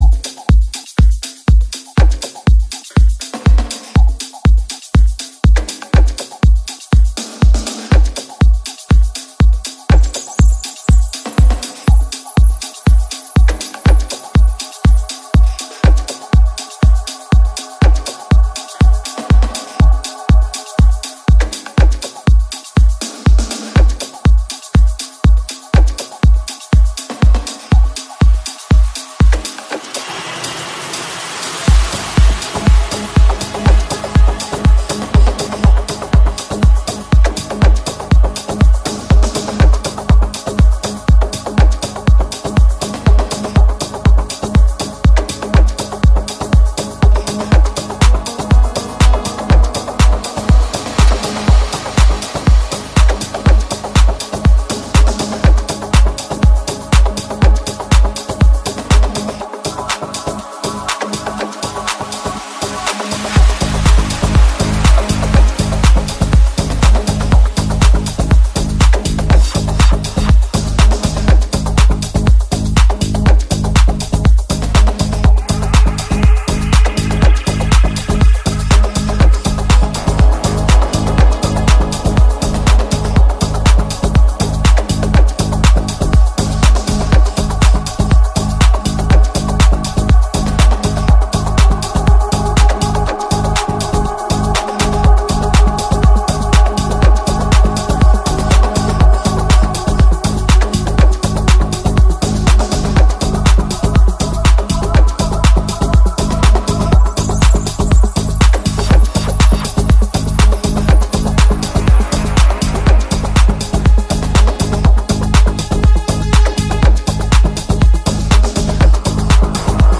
live dj set